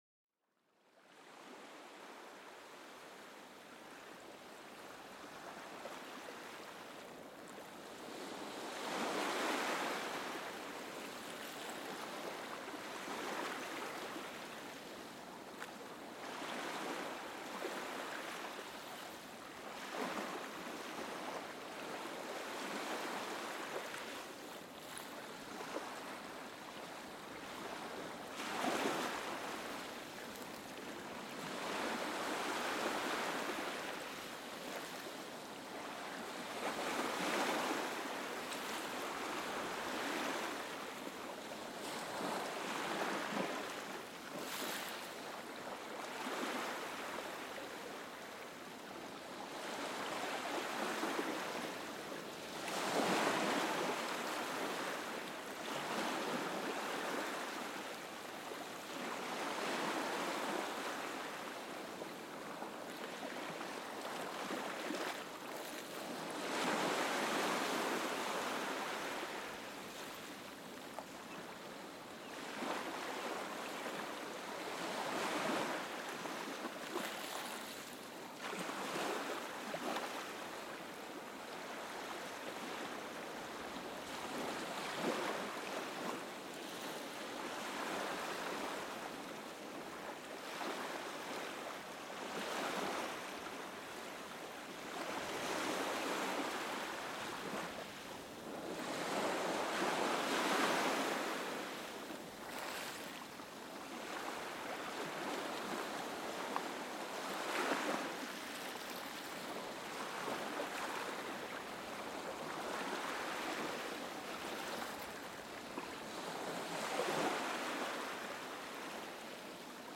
Sumérgete en la tranquilidad de las olas oceánicas en este episodio exclusivo. Descubre cómo el ritmo calmante de las olas puede mejorar tu concentración y reducir la ansiedad. Perfecto para una escucha relajante antes de dormir, este episodio te envuelve en un mundo de calma y serenidad.Este podcast explora los sonidos encantadores de la naturaleza para ayudar a la relajación y fomentar el sueño.